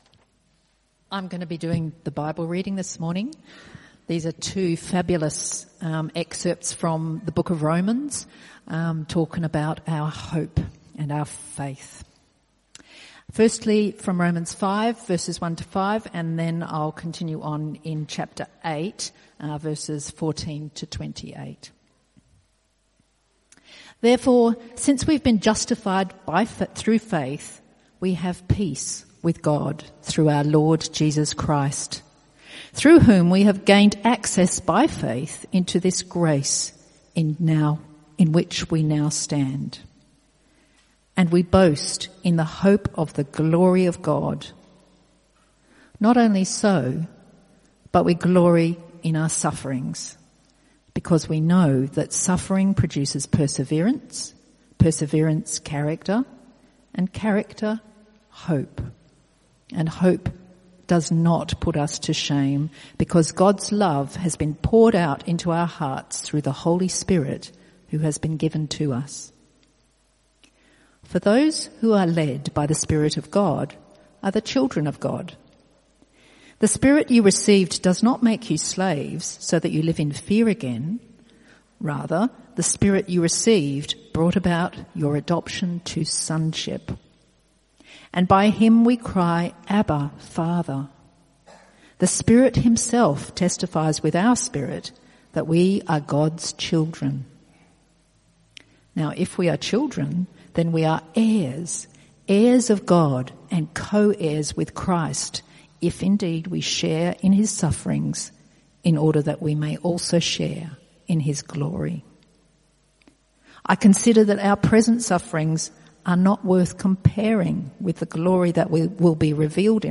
Passage: Romans 8:14-28; Romans 5:1-5 Type: Sermons